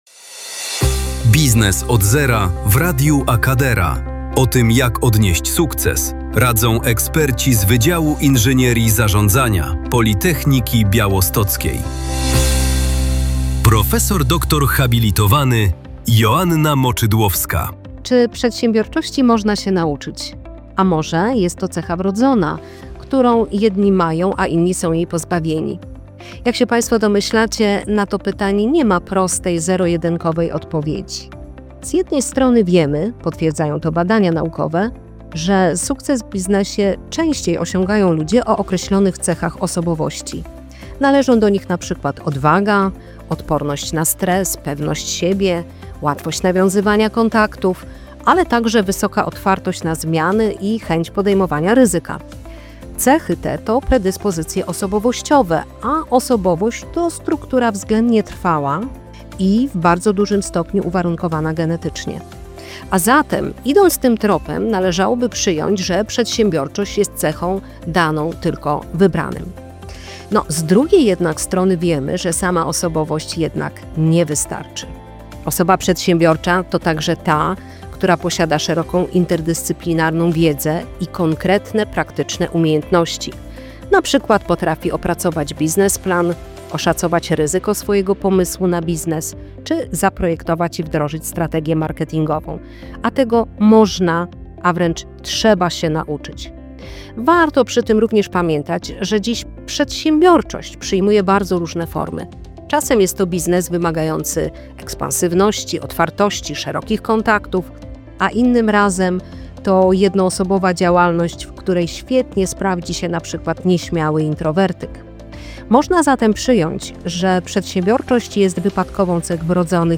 To krótkie, kilkuminutowe felietony eksperckie, w których naukowcy tłumaczą w przystępny sposób, jak stawiać pierwsze kroki w biznesie.
Audycja „Biznes od zera” jest emitowana w każdy czwartek o 10:15 na antenie Radia AKADERA.